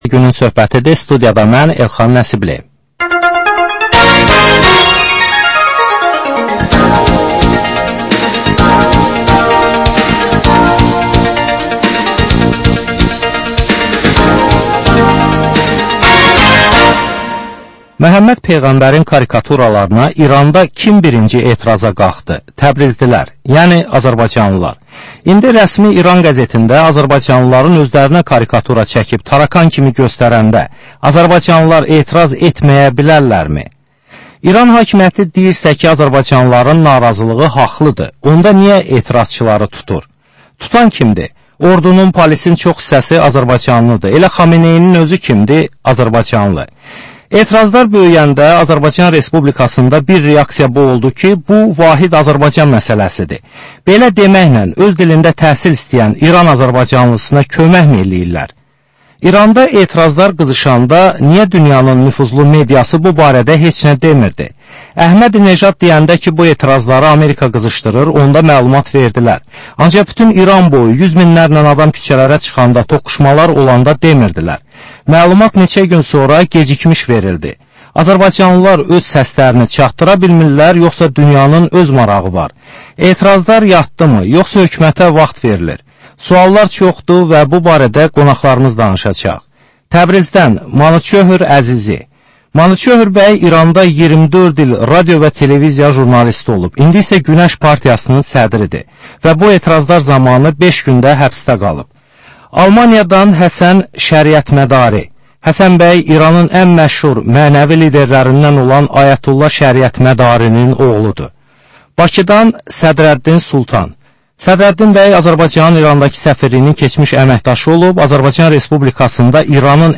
Dəyirmi masa söhbətinin təkrarı.